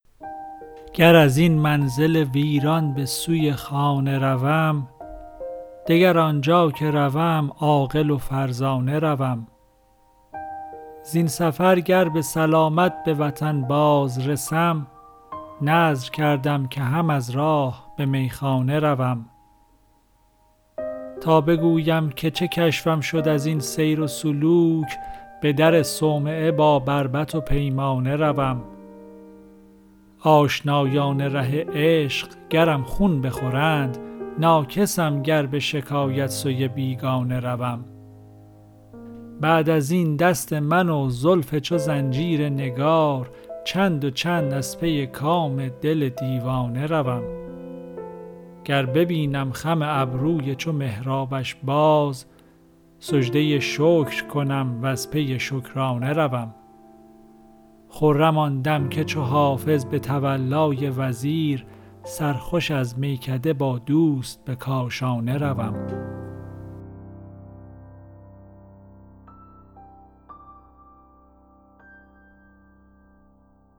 حافظ غزلیات غزل شمارهٔ ۳۶۰ به خوانش